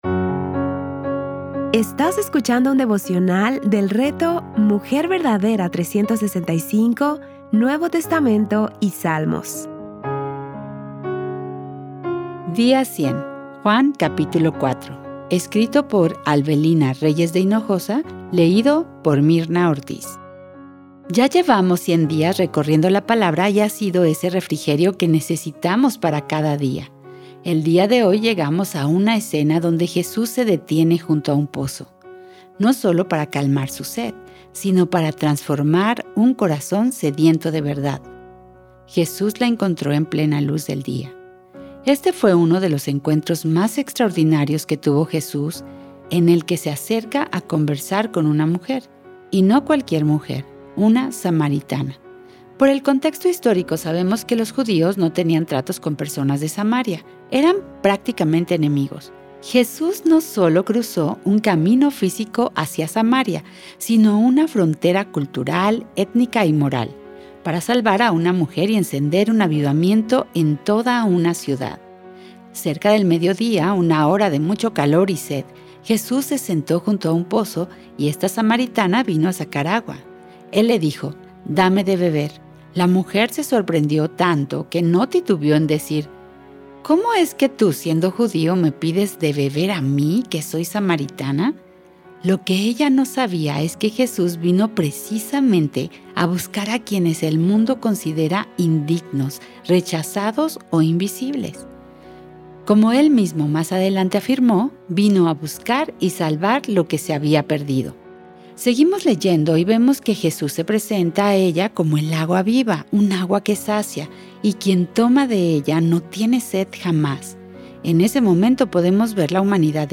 Series:  Juan y Salmos | Temas: Lectura Bíblica